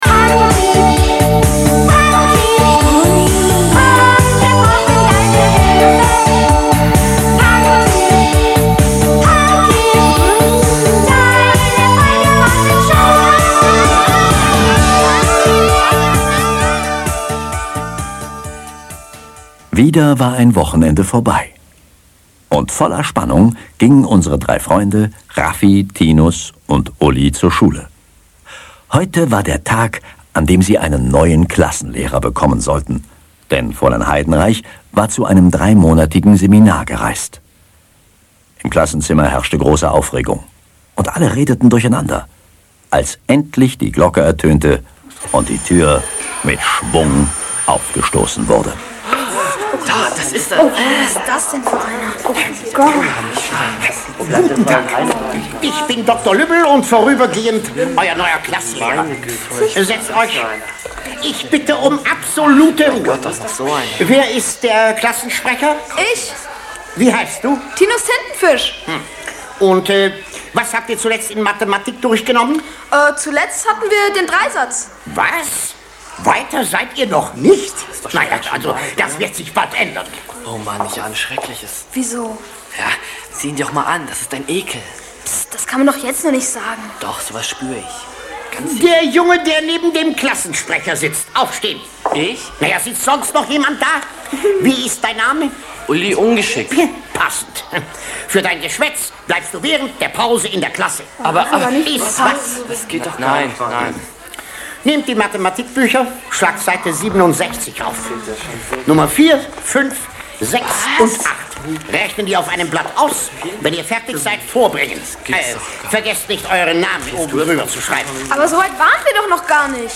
Panki: Alle Folgen der Kinderh�rspiele